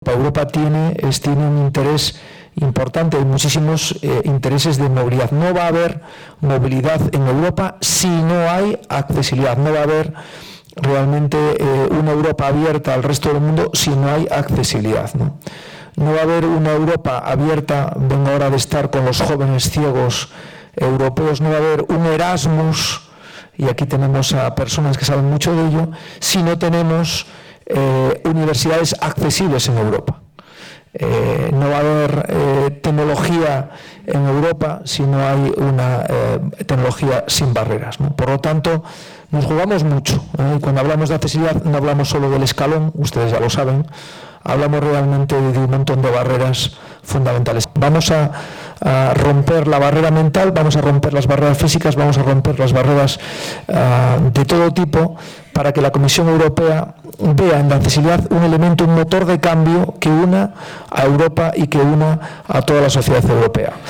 Fundación ONCE acogió, el pasado 7 de noviembre, un encuentro organizado por ‘AccessibleEU’ para impulsar el diálogo entre industria, administraciones públicas y sociedad civil con el objetivo de mejorar la accesibilidad.